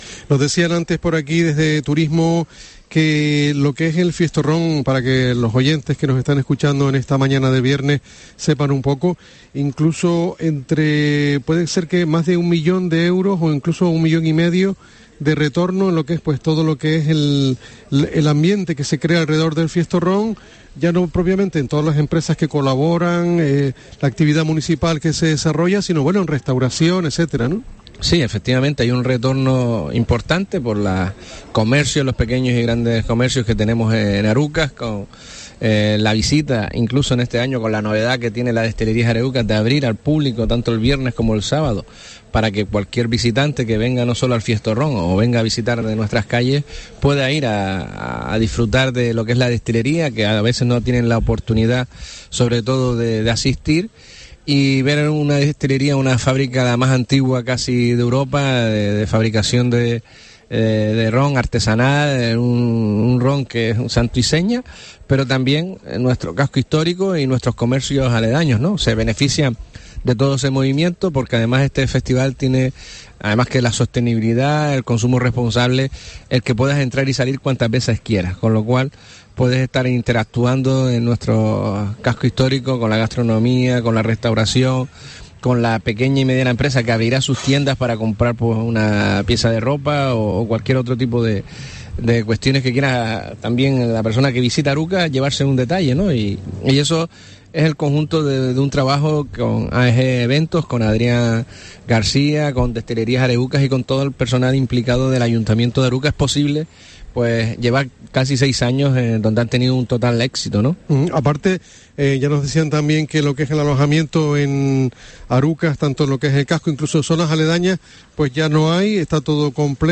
Juan Jesús Facundo, alcalde de Arucas, anima a que acudamos durante el fin de semana al Fiestoron